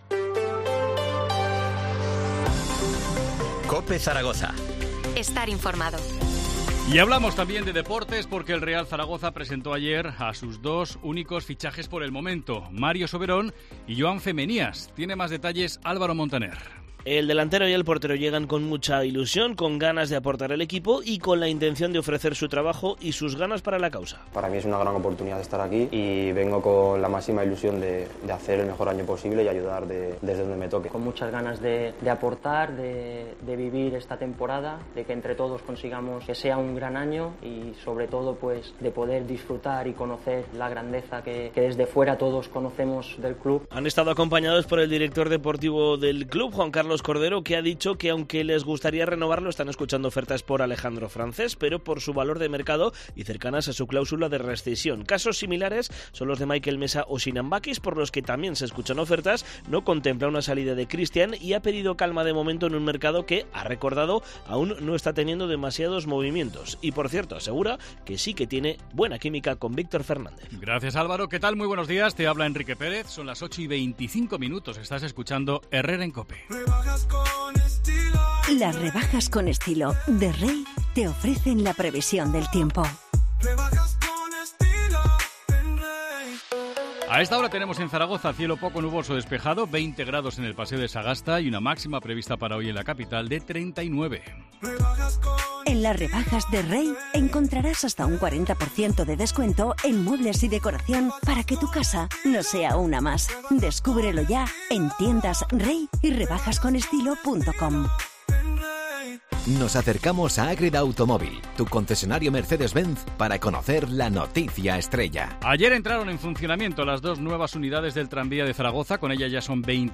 AUDIO: Titulares del día en COPE Zaragoza